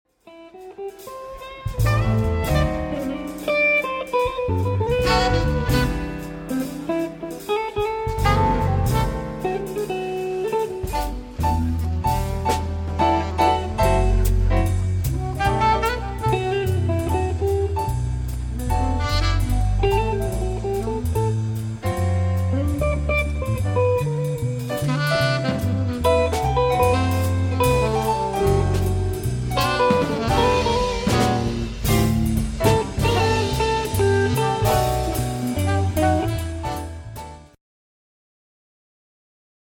Jazz, Standards